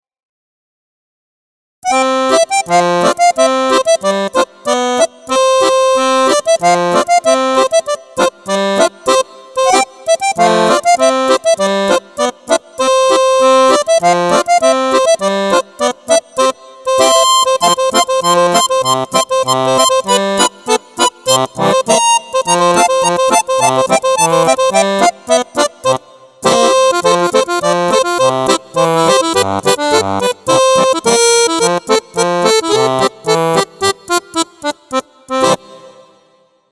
Мелодии на баяне